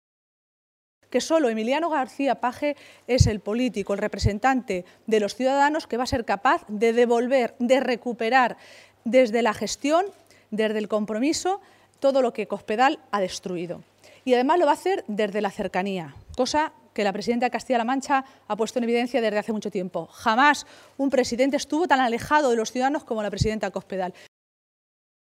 Maestre se pronunciaba de esta manera esta tarde, en una comparecencia ante los medios de comunicación minutos antes de que se reuniera en Toledo la dirección regional socialista.